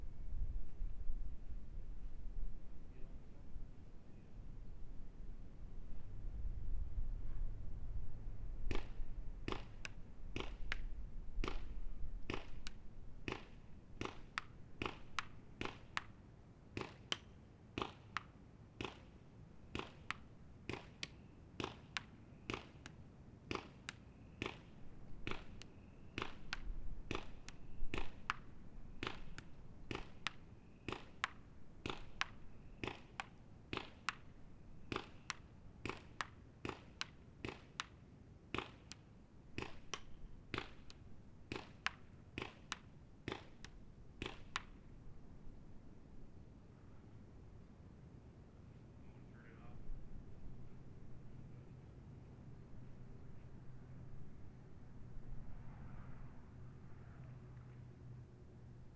splats_telephone_pol..>2009-02-22 20:07 5.4M